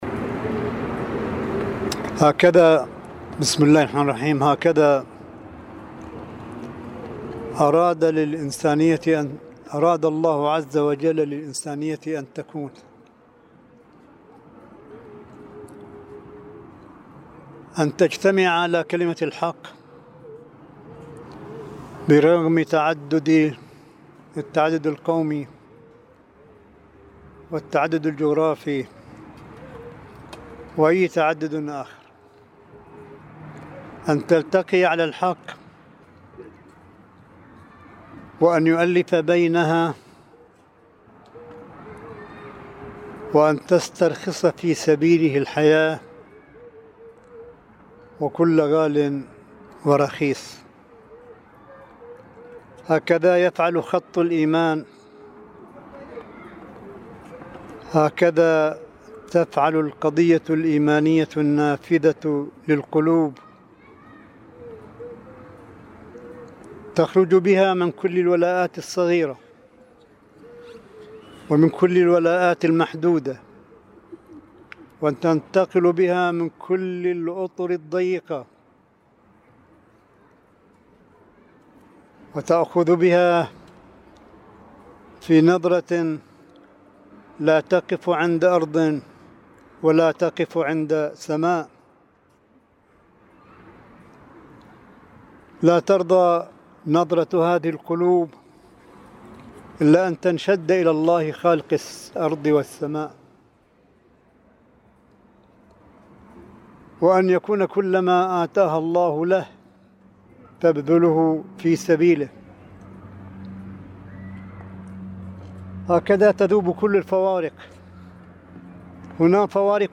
صوت : كلمة آية الله قاسم عند زيارته لقبور الشهداء في عيد الأضحى – قم المقدسة 2019 م
ملف صوتي لكلمة سماحة آية الله الشيخ عيسى أحمد قاسم عند زيارته لقبور شهداء البحرين ومدافعي الحرم في أول ايام عيد الأضحى المبارك – مدينة قم المقدسة 12 أغسطس 2019 م